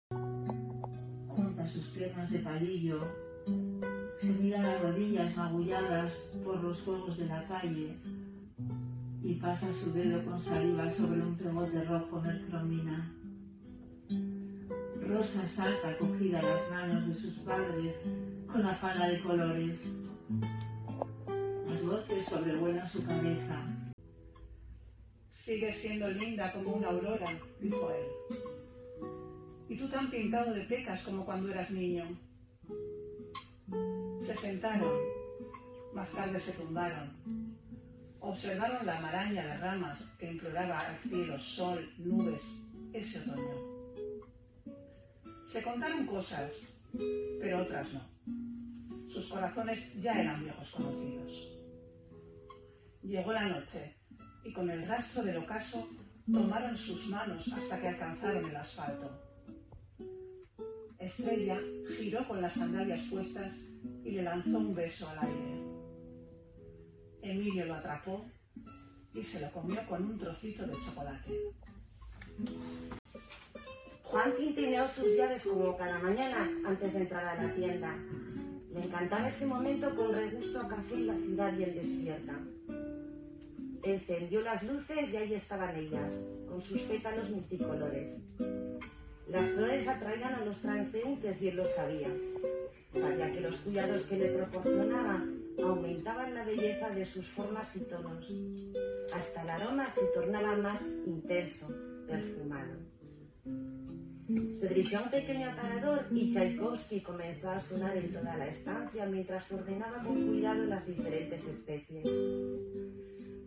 algunos relatos de su libro formato MP3 audio(1,45 MB) en colaboración con compañeras tanto en formato tinta como en braille.
CORTE3.Recital.mp3